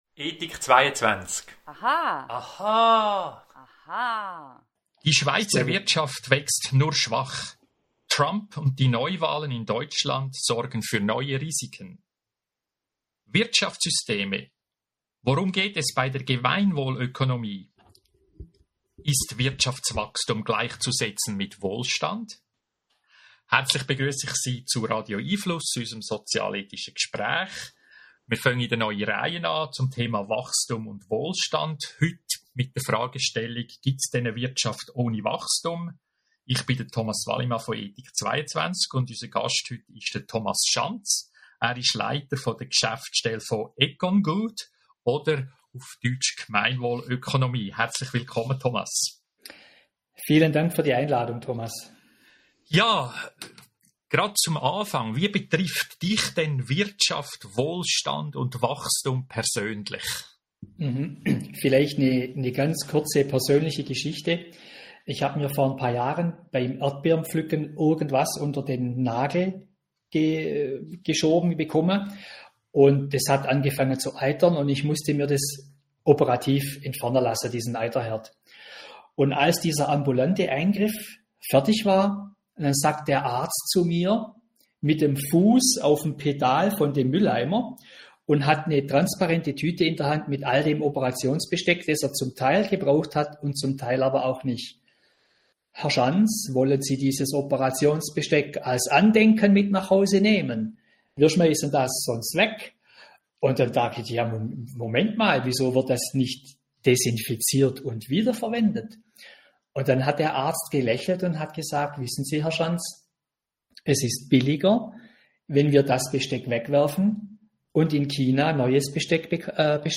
Verschiedene Tropfen, unterschiedliche Strömungen kommen zusammen im Gespräch.